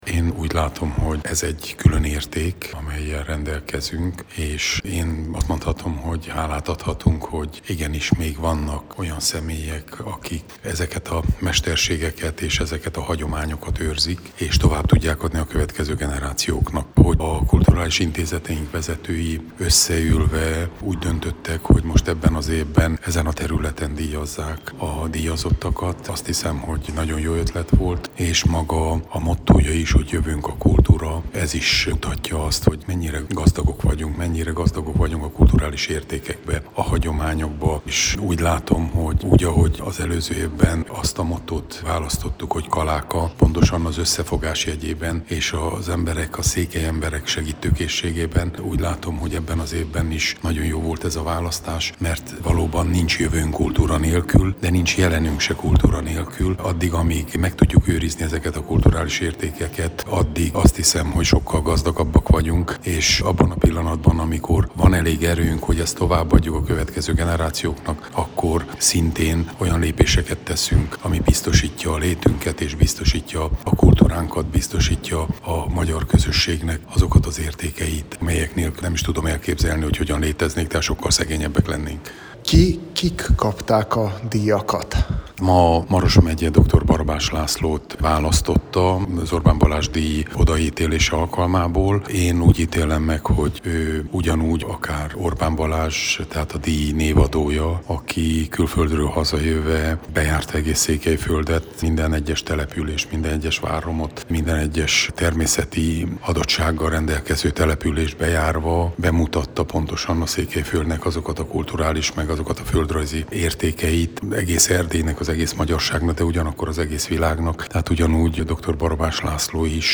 Az ünnepélyes díjátadóra október 1-én került sor Marosvásárhelyen, a Kultúrpalotában.